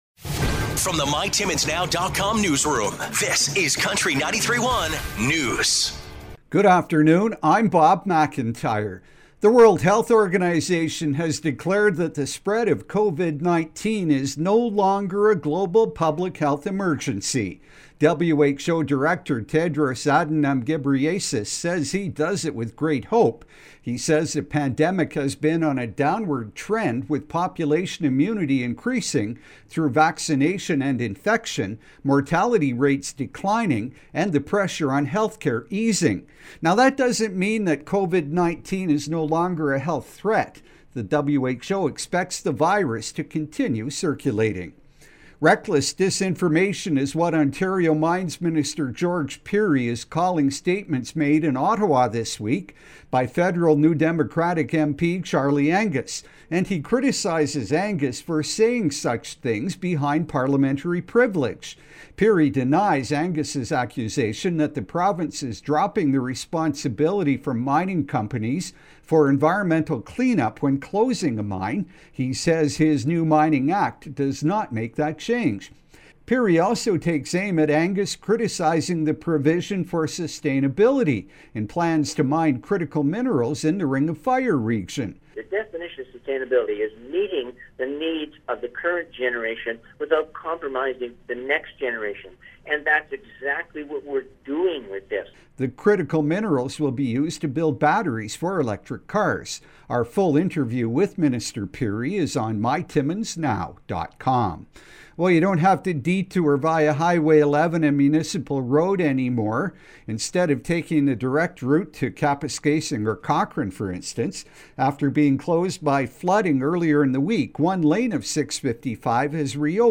5:00pm Country 93.1 News – Fri., May 5, 2023